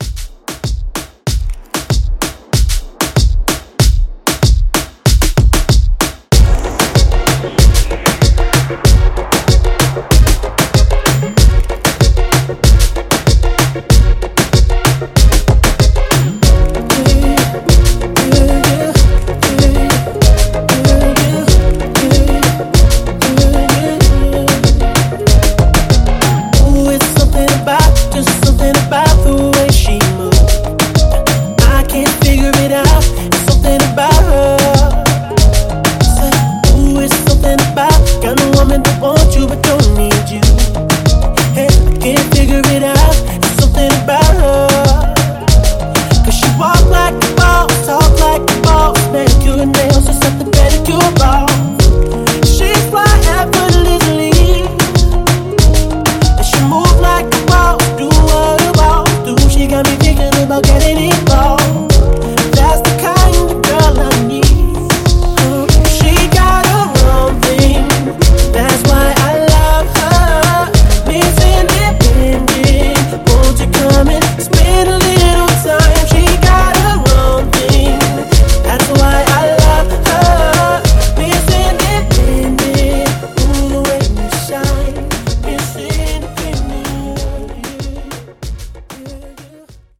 Genres: FUTURE HOUSE , MASHUPS
Clean BPM: 122 Time